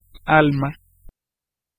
Ääntäminen
France: IPA: /ɛs.pʁi/